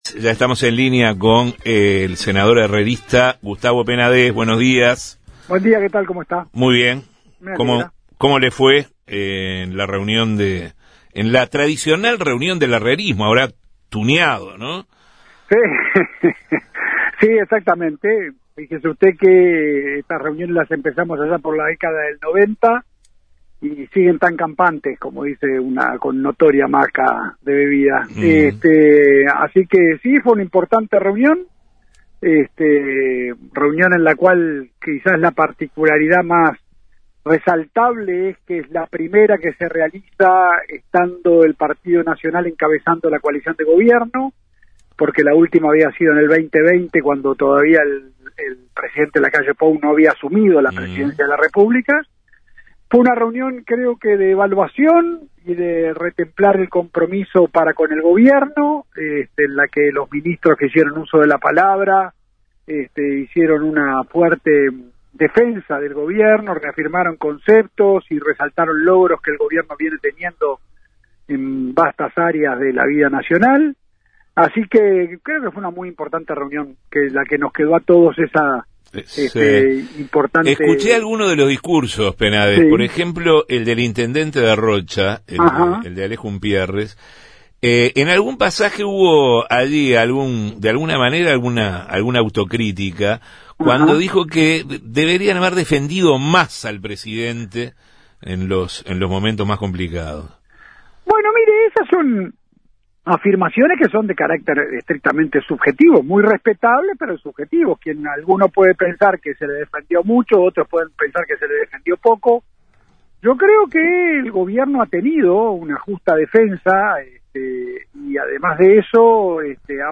En Justos y pecadores entrevistamos al senador nacionalista Gustavo Penadés, sobre el encuentro de Todos hacia adelante, realizado en La Paloma este sábado pasado